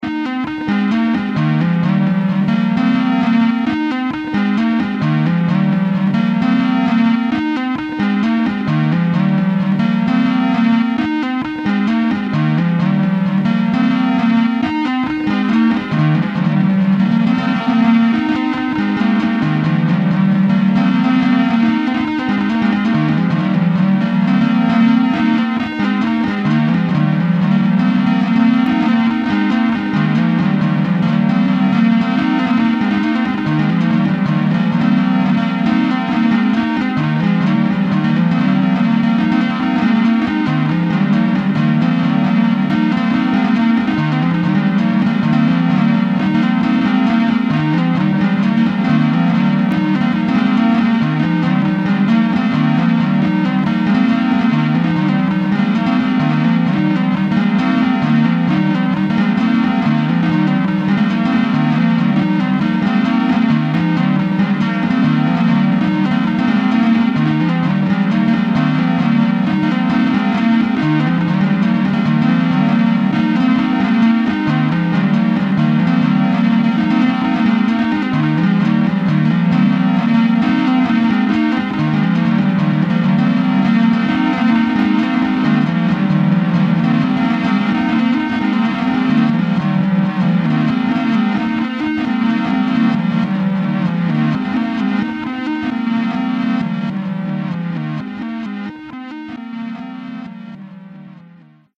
Low-rent phase music made affordable.